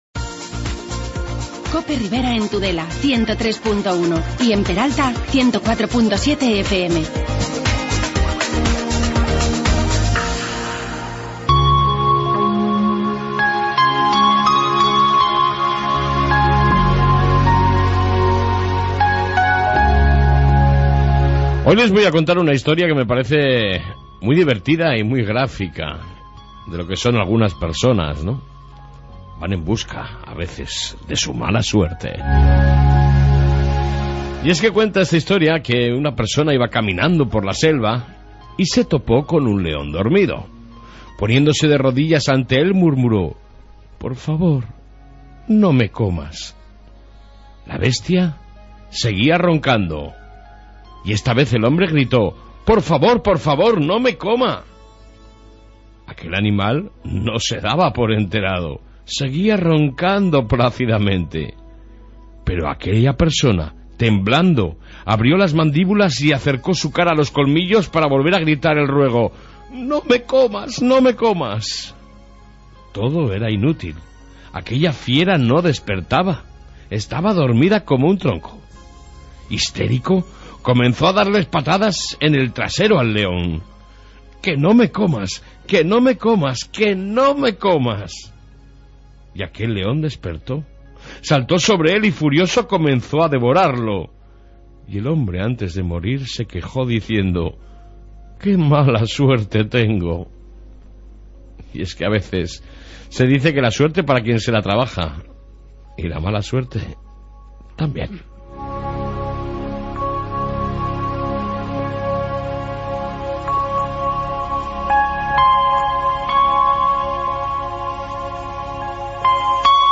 AUDIO: reflexió matutina, informe de Policia Municipal y amplia entrevista con el nuevo Alcalde de Castejón David Álvarez